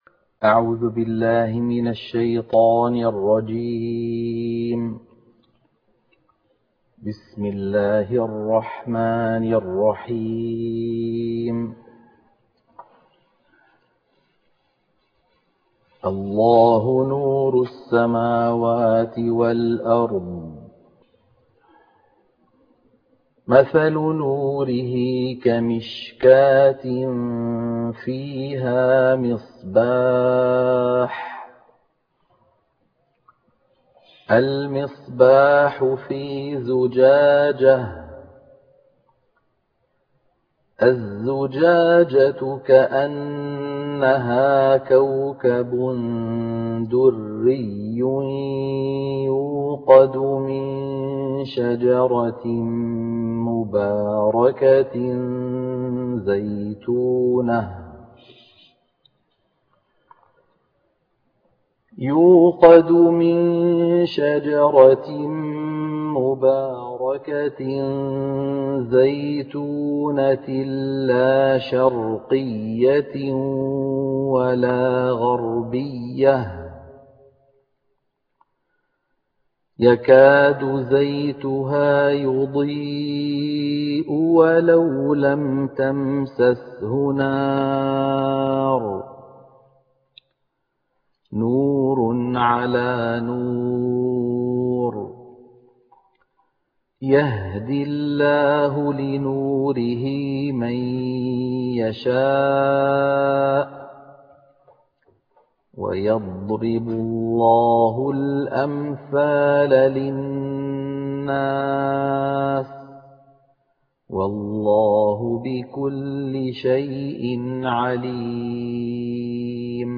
تلاوة خاشعة من سورة النور - الشيخ أيمن سويد
عنوان المادة تلاوة خاشعة من سورة النور